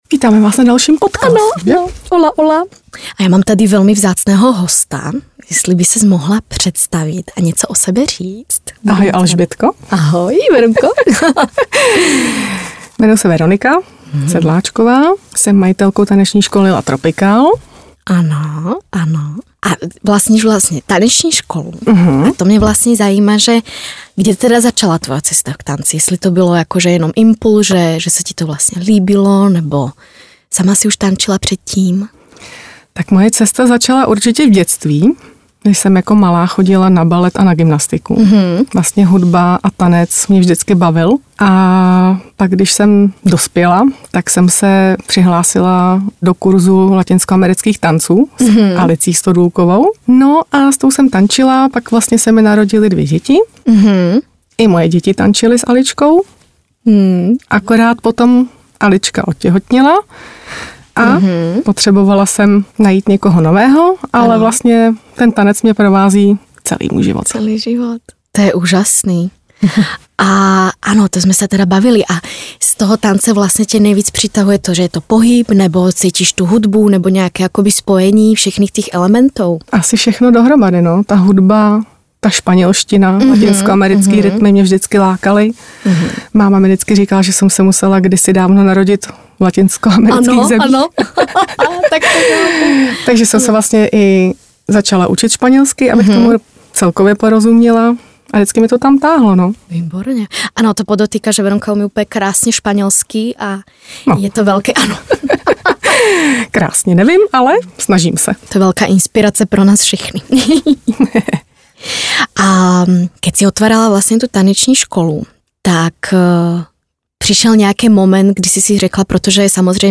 Tropical podcast  Rozhovor